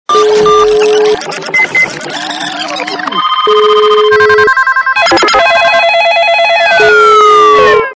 При прослушивании Звуки - в офисе качество понижено и присутствуют гудки.
Звук Звуки - в офисе